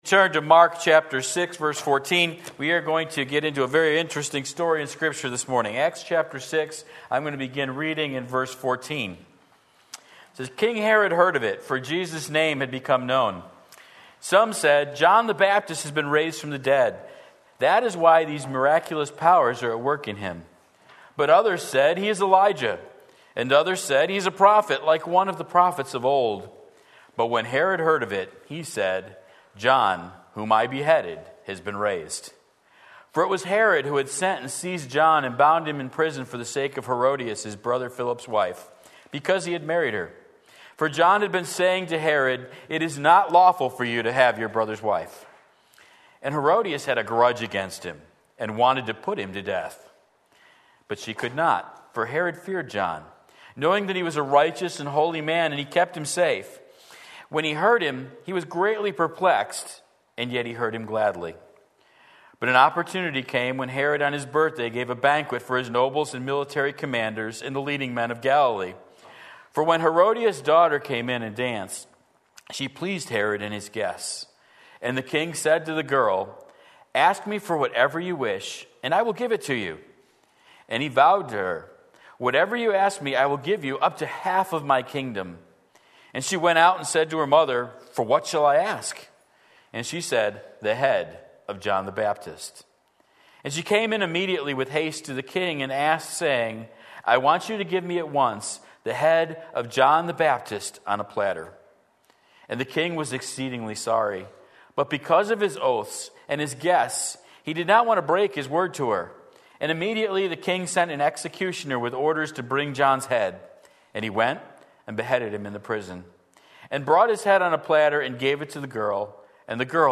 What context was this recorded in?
Reactions to a Faithful Servant Mark 6:14-29 Sunday Morning Service